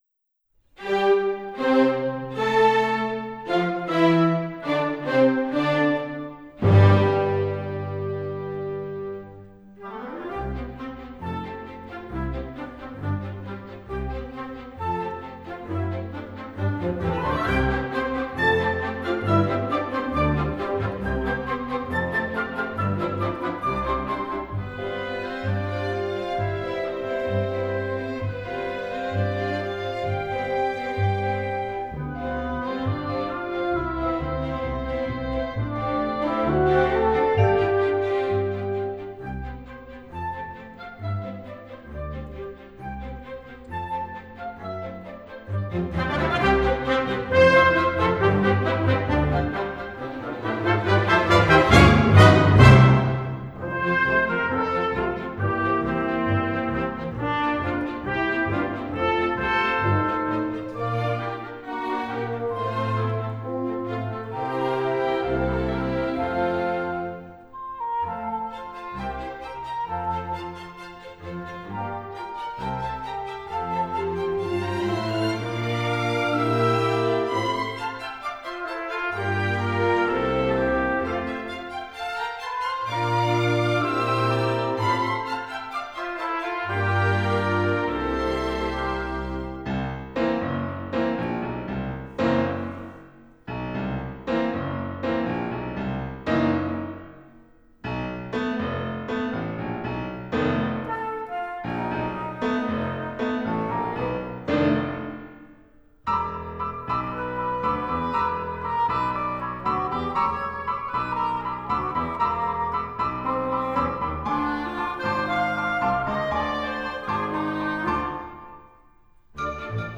Kirjalevy ja koko perheen konsertti
kaksitoista ihastuttavaa laulua lapsille